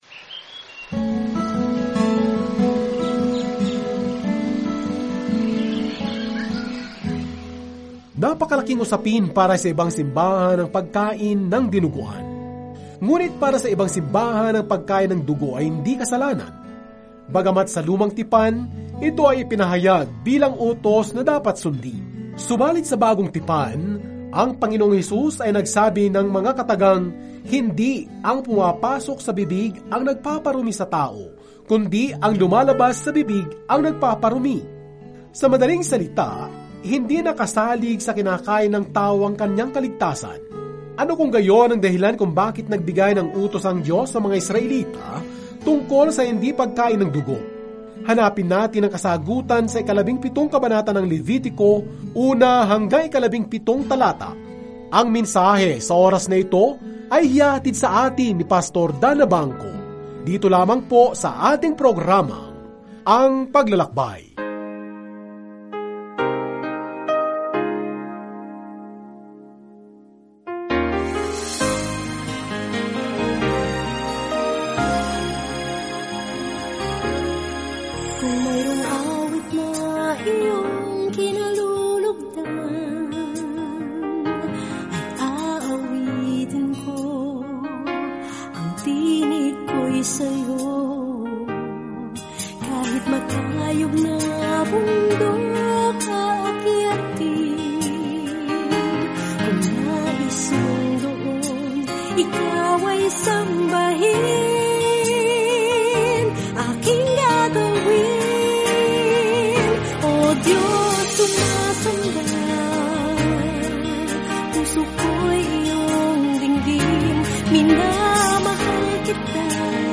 Sa pagsamba, paghahain, at pagpipitagan, sinasagot ng Levitico ang tanong na iyan para sa sinaunang Israel. Araw-araw na paglalakbay sa Leviticus habang nakikinig ka sa audio study at nagbabasa ng mga piling talata mula sa salita ng Diyos.